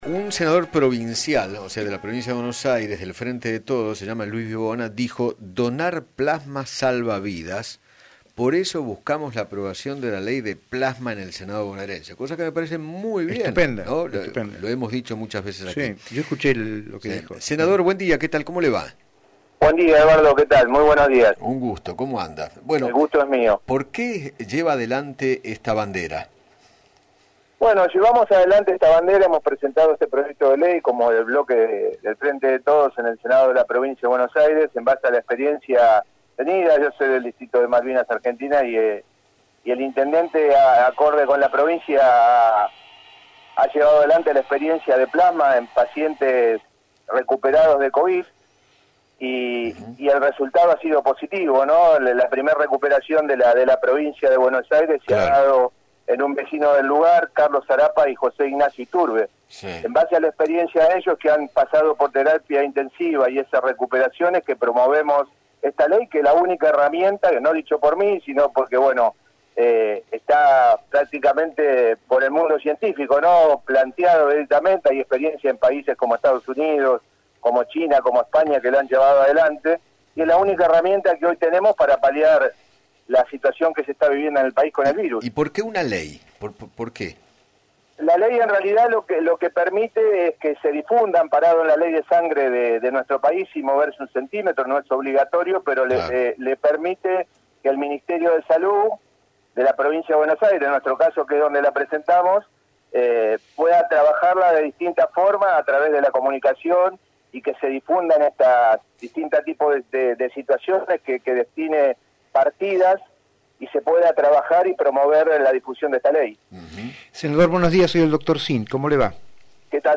Luis Vivona, senador bonaerense, dialogó con Eduardo Feinmann sobre el proyecto que presentó para que sea ley la donación de plasma para tratar el Covid-19.